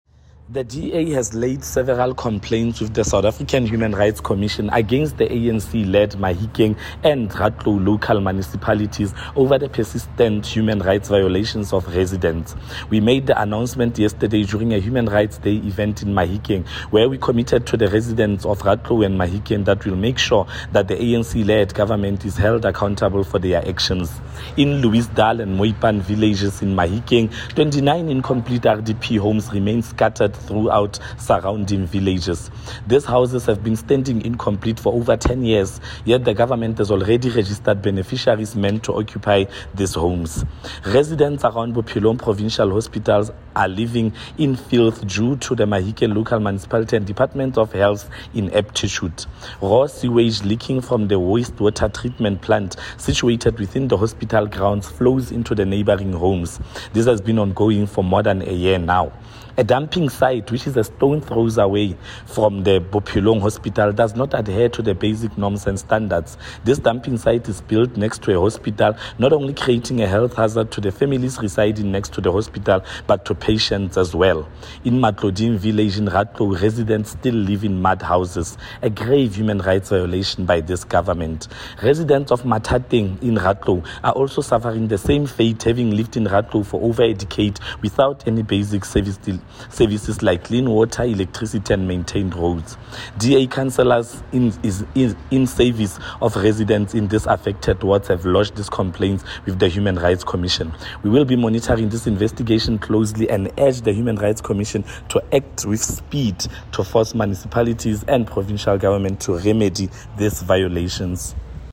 Note to Broadcasters: Find linked soundbites in